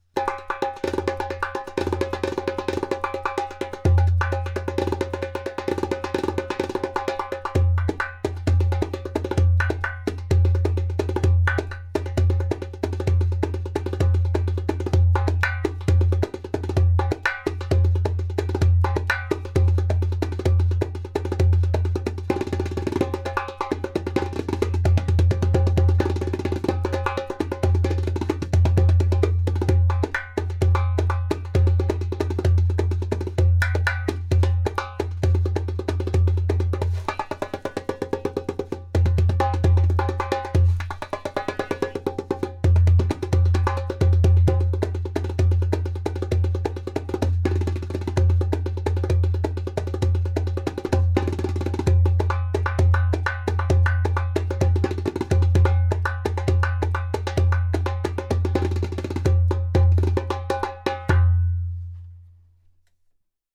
• Strong and super easy to produce clay kik (click) sound
• Beautiful harmonic overtones.
• Skin: Dotted goat skin